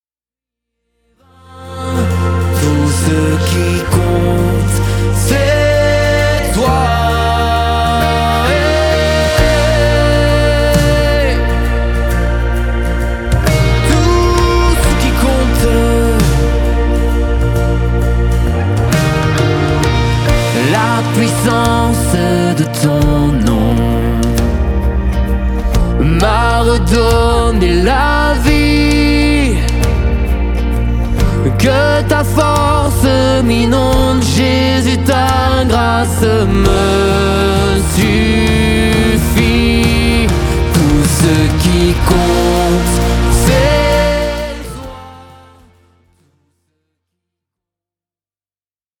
pop louange